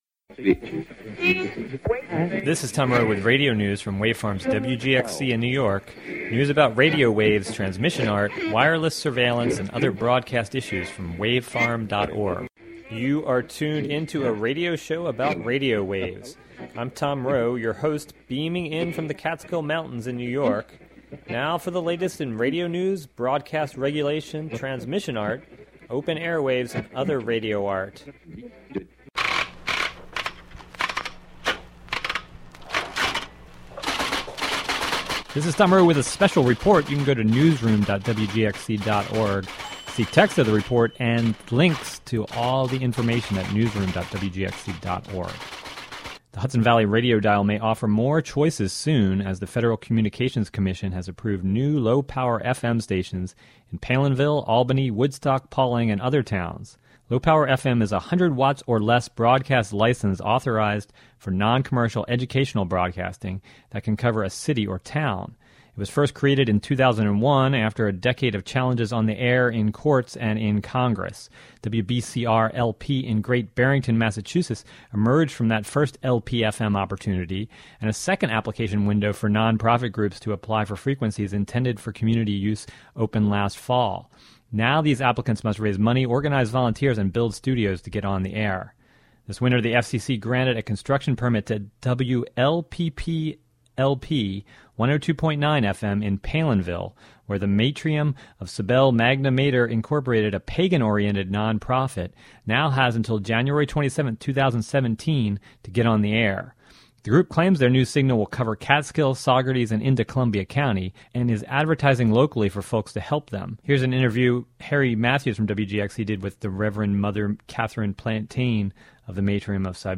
(28:05) Interviews and news about new low-power community radio stations possibly coming to Palenville, Troy, Albany, Woodstock, Stamford, Pawling, and other Hudson Valley communities.